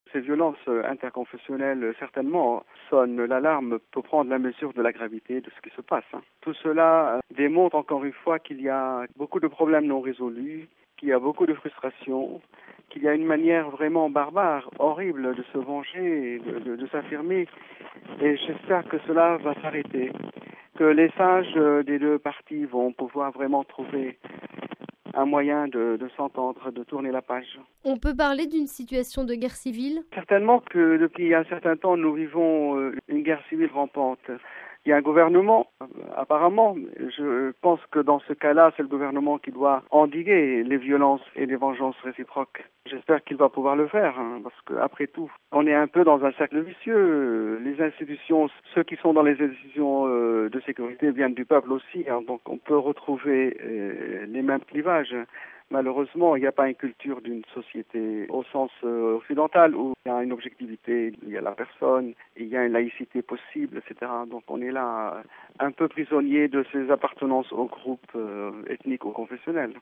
Le témoignage de Monseigneur Jean Sleiman, de l’archevêché latin de Bagdad RealAudio